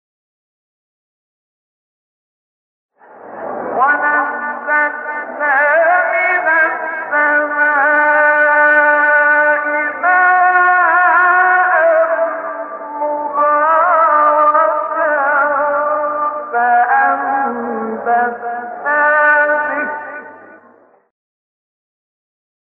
سایت قرآن کلام نورانی - سه گاه منشاوی (1).mp3
سایت-قرآن-کلام-نورانی-سه-گاه-منشاوی-1.mp3